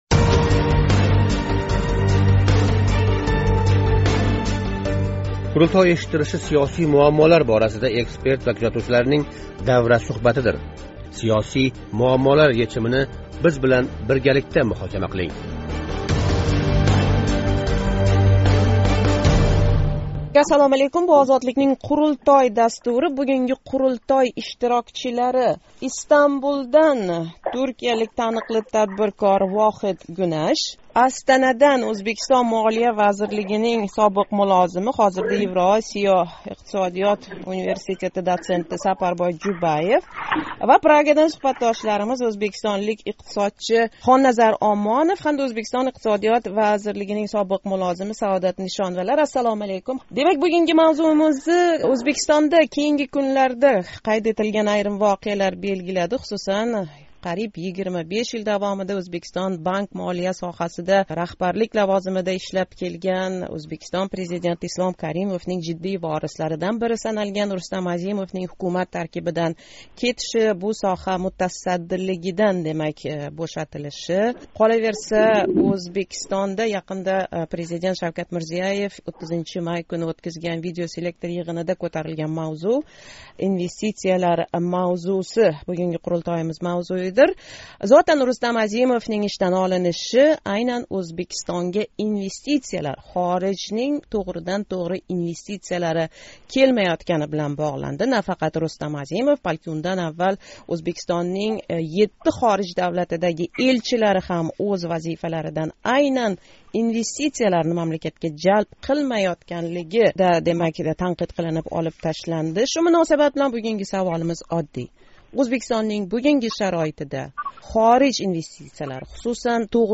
Бу савол "Қурултой" муҳокамасига қўйилди.